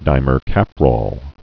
(dīmər-kăprôl, -rōl)